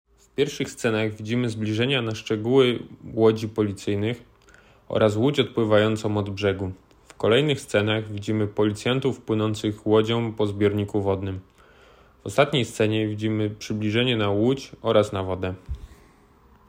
Opis nagrania: audiodeskrypcja do filmu